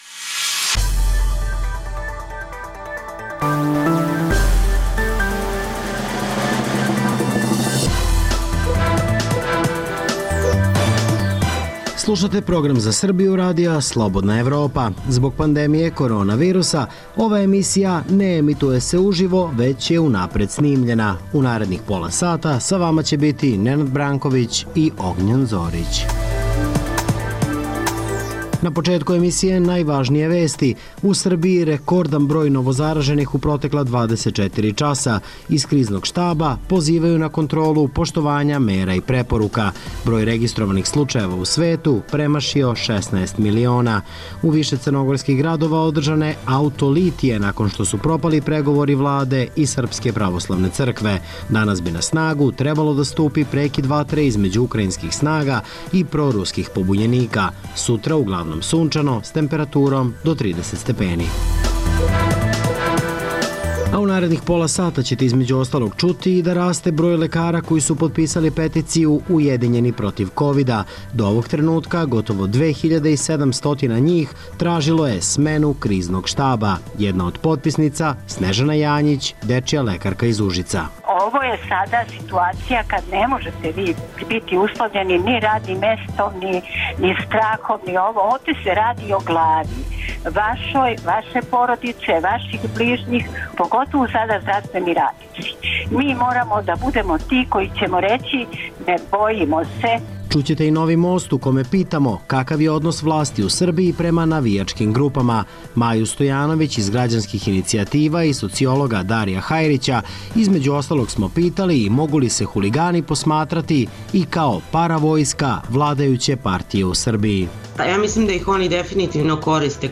Zbog pandemije korona virusa, ova emisija se ne emituje uživo, već je unapred snimljena. Raste broj lekara koji su potpisali peticiju „Ujedinjeni protiv kovida“.